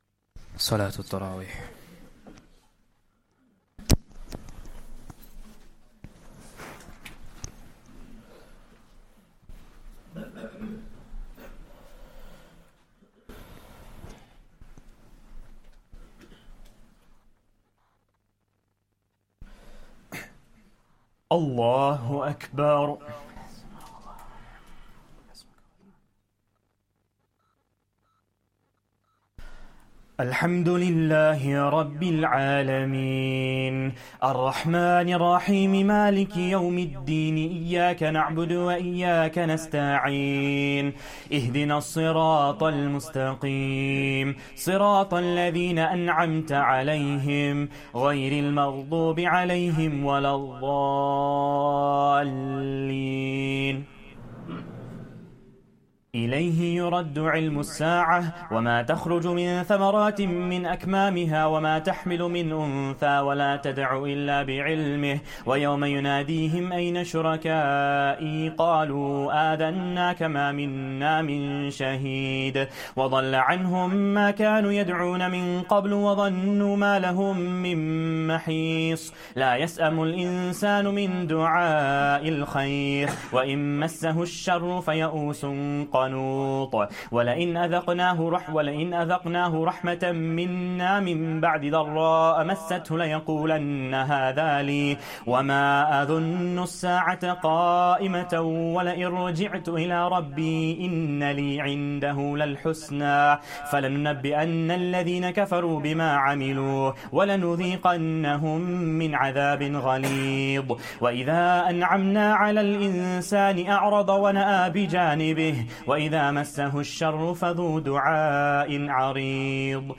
Taraweeh Prayer 21st Ramadan